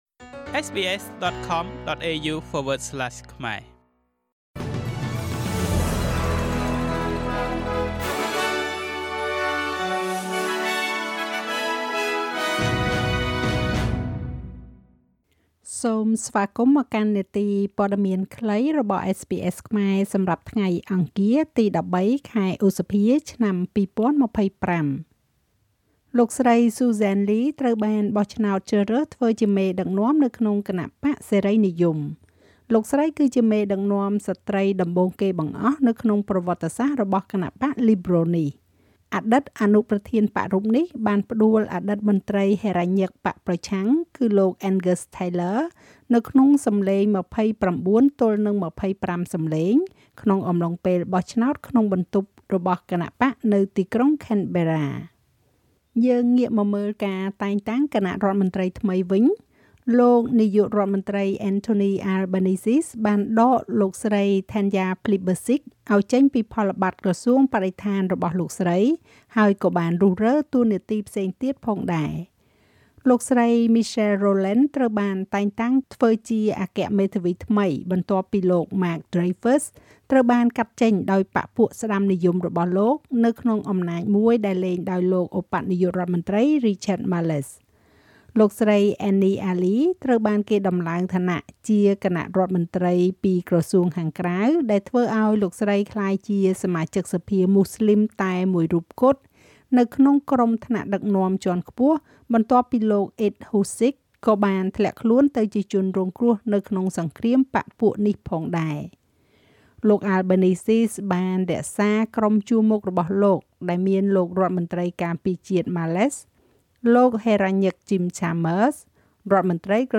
នាទីព័ត៌មានខ្លីរបស់SBSខ្មែរ សម្រាប់ថ្ងៃអង្គារ ទី១៣ ខែឧសភា ឆ្នាំ២០២៥